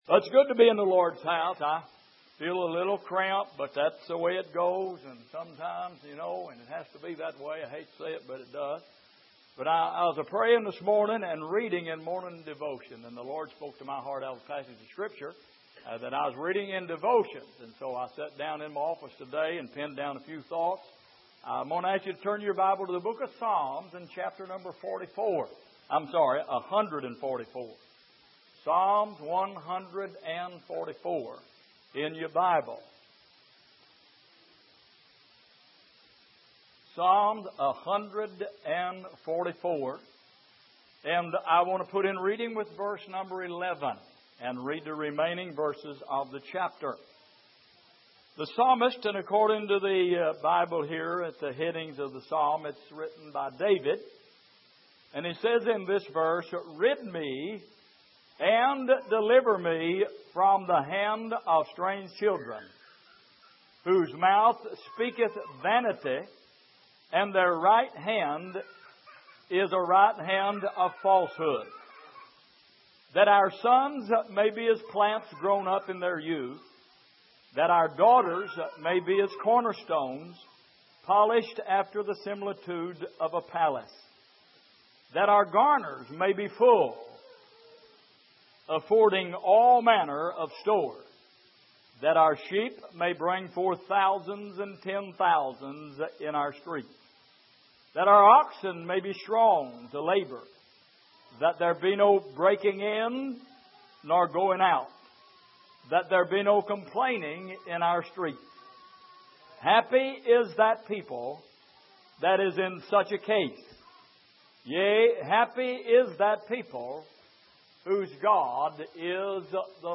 Passage: Psalm 144:11-15 Service: Midweek